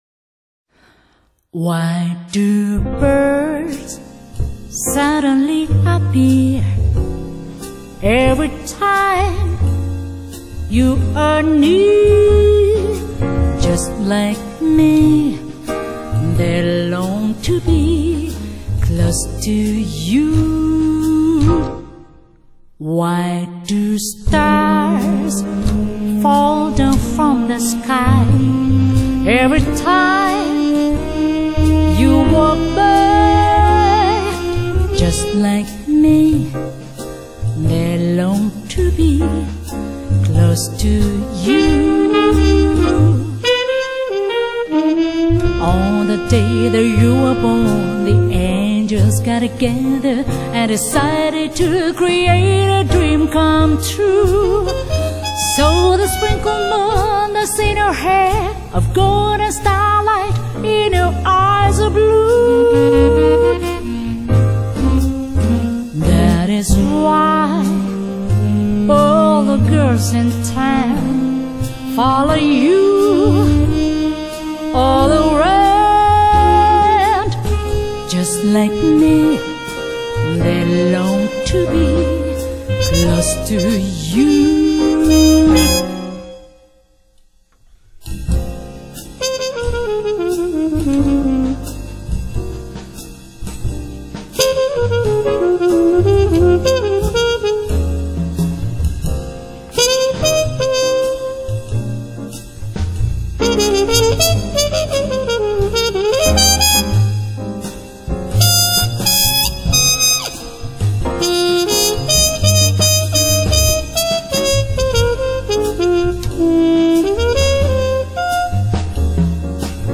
Female Vocal Jazz